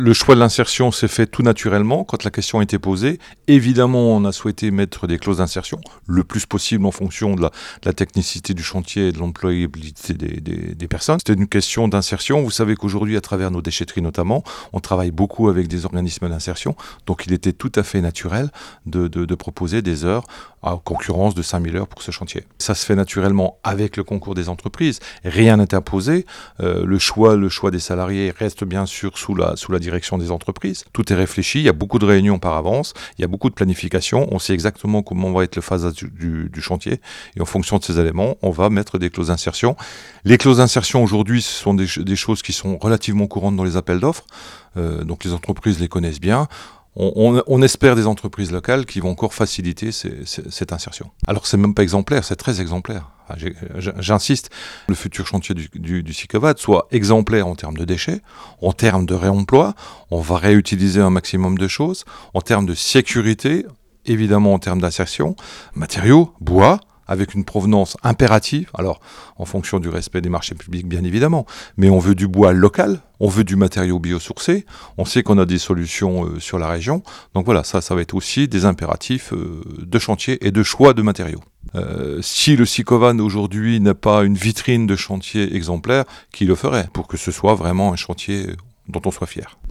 Interview de Philippe Claudon président du Sicovad.